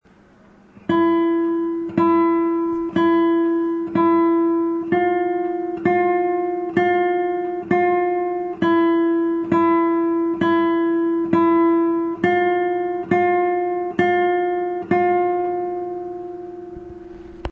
تمریتاتی با استفاده از نت (می.فا)